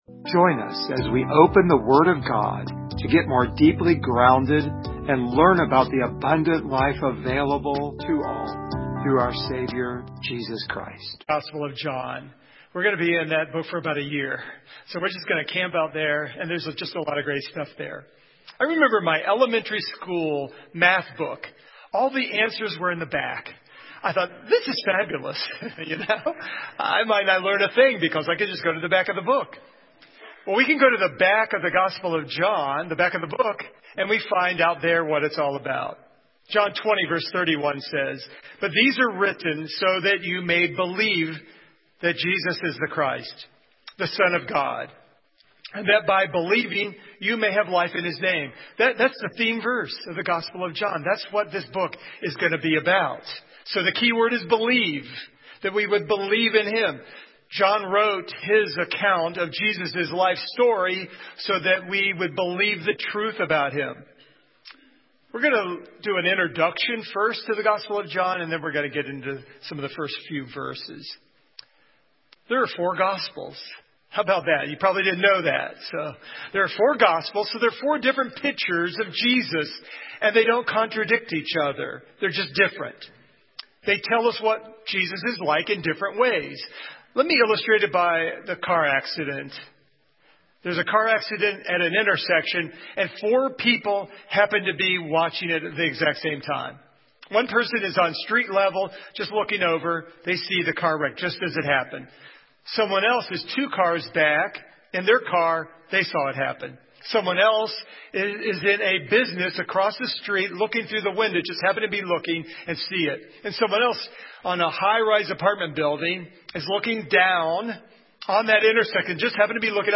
Service Type: Sunday Morning
Download Files Notes Topics: Christ , Deity of Jesus , God , Incarnation , Trinity share this sermon « How Do I Face The Future?